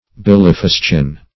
Search Result for " bilifuscin" : The Collaborative International Dictionary of English v.0.48: Bilifuscin \Bil`i*fus"cin\, n. [L. bilis bile + fuscus dark.]